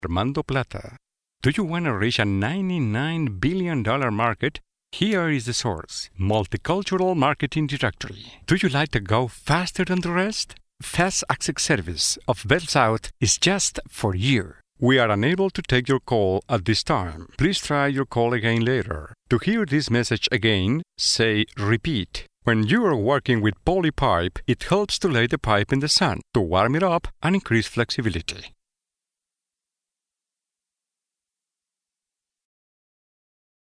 His elegant, multitalented and distinctive delivery is recognized as the very best in the industry.
spanischer Sprecher (Südamerika) voice-talent voice-over Colombian Native Locutor Neutro Colombiano
Sprechprobe: eLearning (Muttersprache):